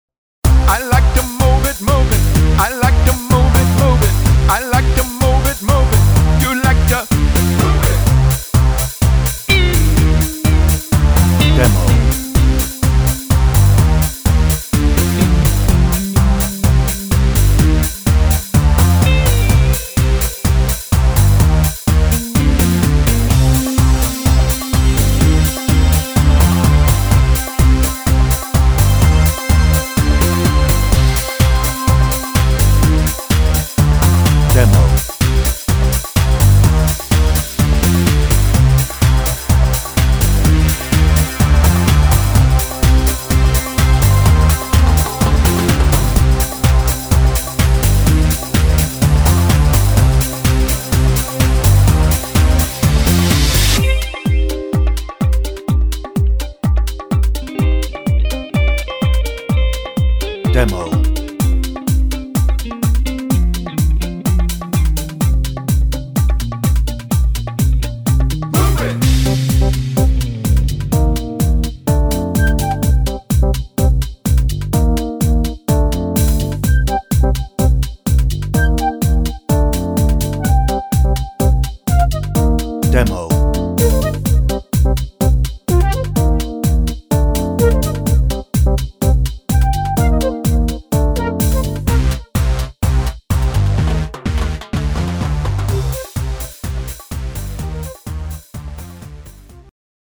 Hoedown - no ref vocal
Instrumental